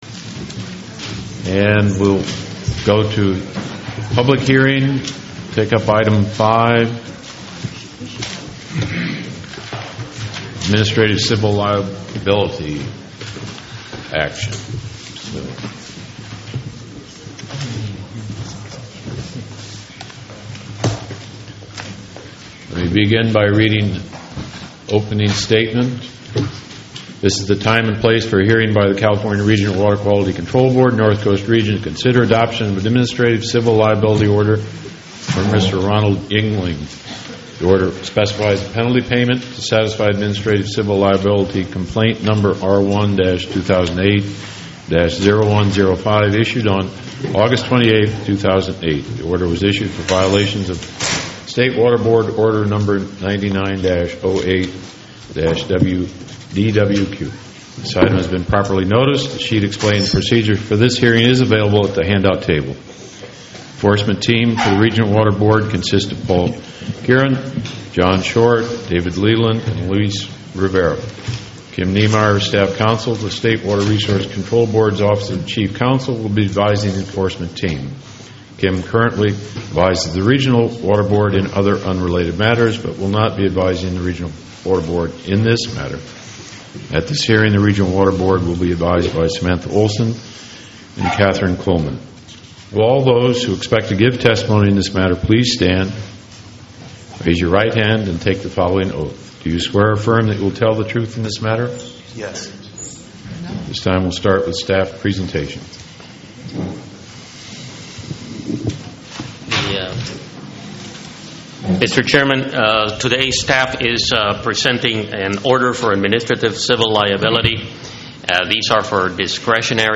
10/2008 Baord Meeting | California Northcoast Regional Water Quality Control Board